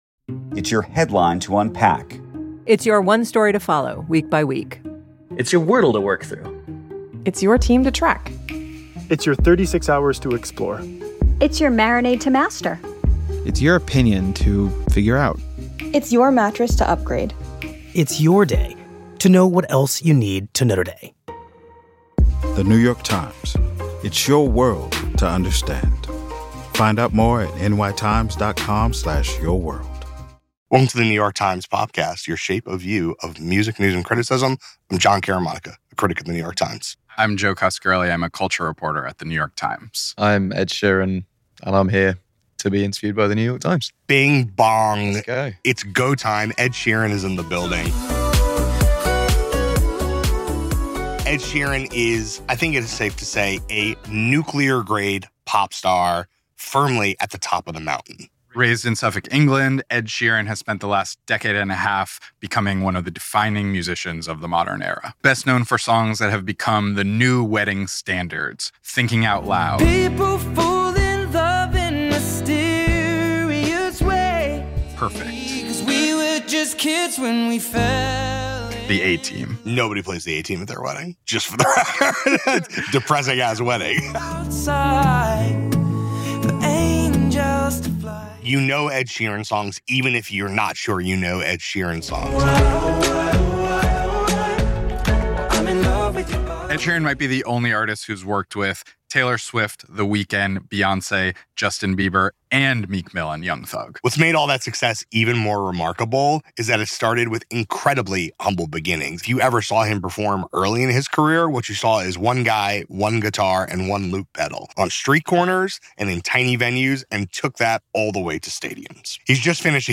Ed Sheeran Interview!